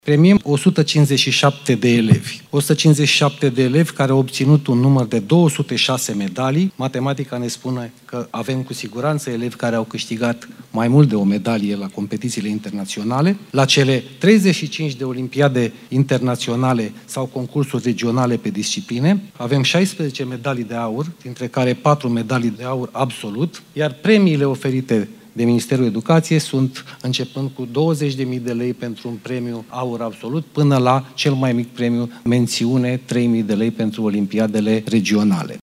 Secretarul de stat în Ministerul Educației, Sorin Ion: „Avem elevi care au câștigat mai mult de o medalie la competițiile internaționale”